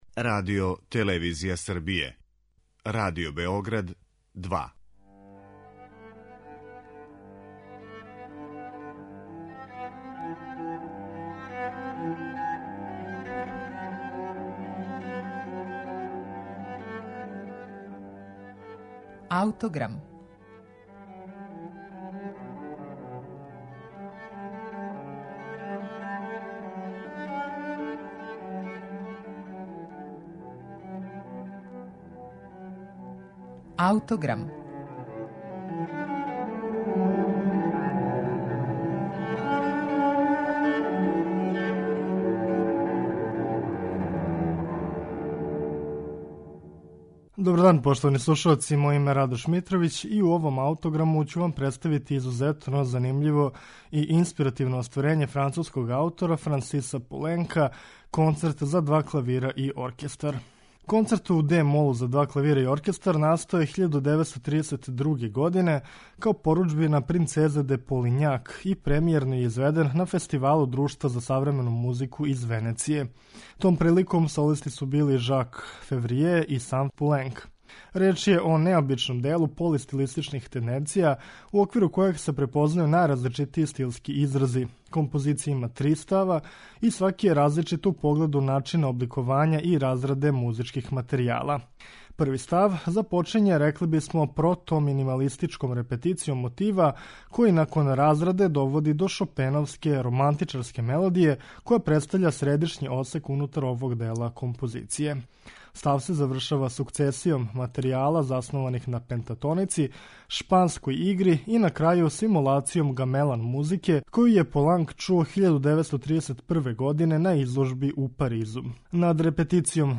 Концерт у де-молу за два клавира и оркестар, Франсиса Пуленка, настао је 1932. године као поруџбина принцезе Де Полињак.
Реч је о делу занимљиве драматургије и полистилистичних тенденцијa, у коме се препознају утицаји музике класицизма, романтизма, али и гамелан оркестра са Балија.
Концерт за два клавира и оркестар Френсиса Пуленка слушаћемо у интерпретацији Артура Голда, Роберта Фицдејла и оркестра Њујоршке филхармоније, под управом Ленарда Бернштајна.